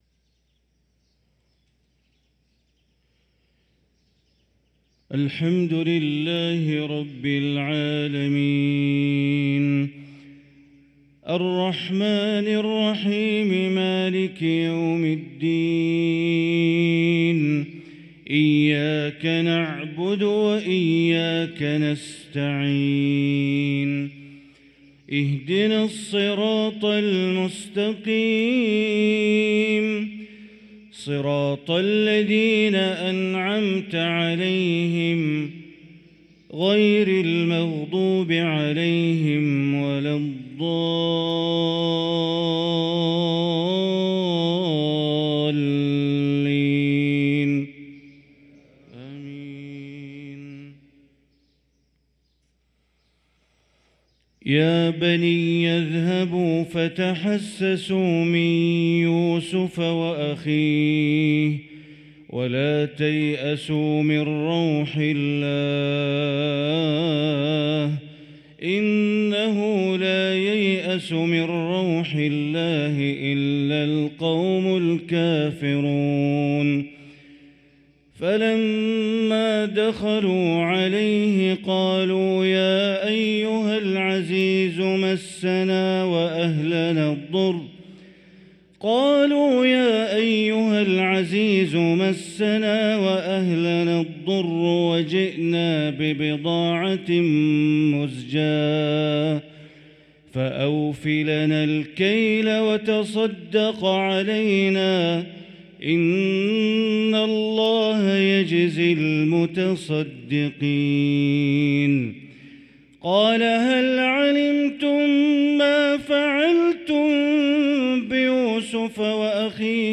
صلاة الفجر للقارئ بندر بليلة 12 ربيع الآخر 1445 هـ